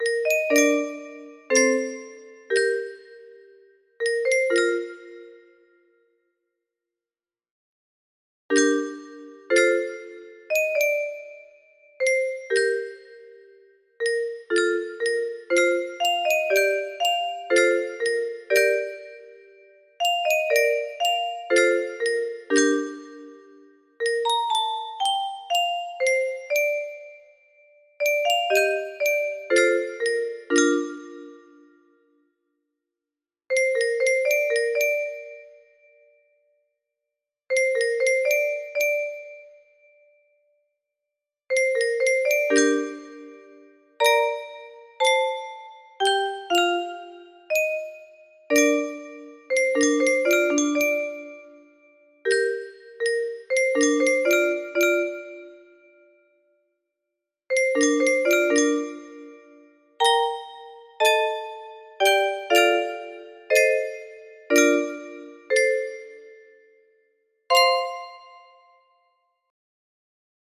BPM 60